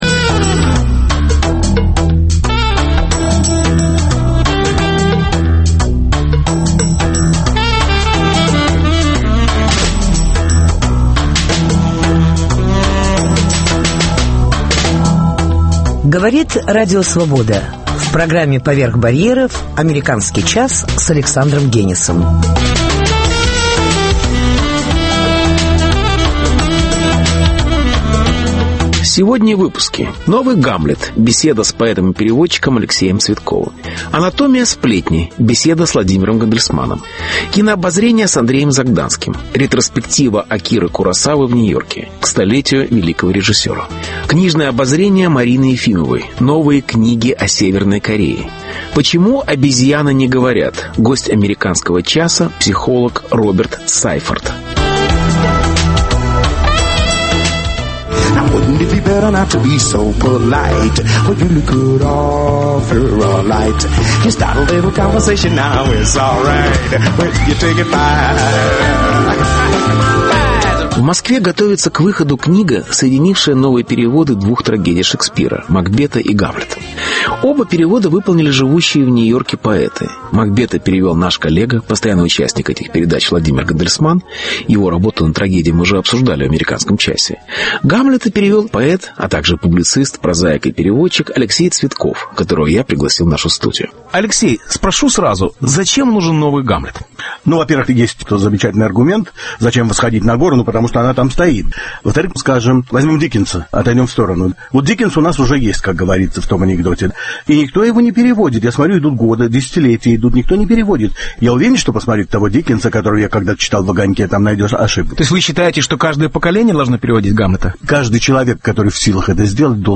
Беседа с поэтом и переводчиком Алексеем Цветковым.